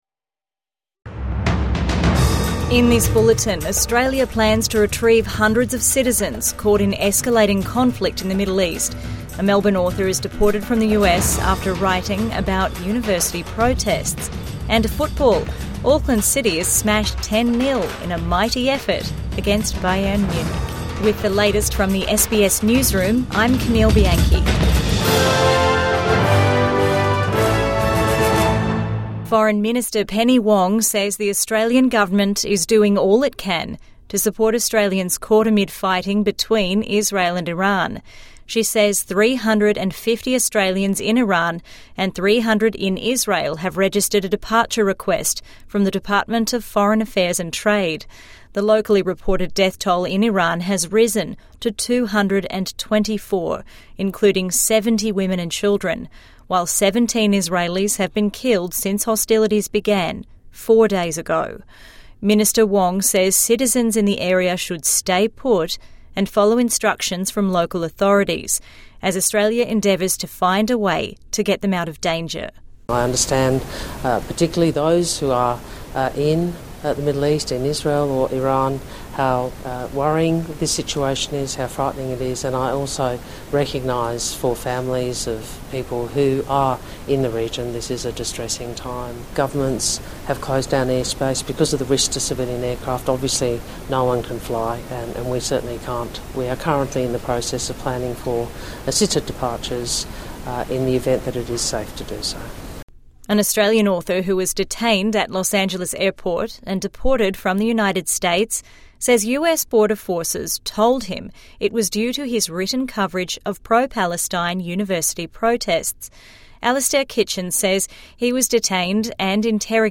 Australia plans safe passage for citizens caught in Iran, Israel | Evening News Bulletin 16 June 2025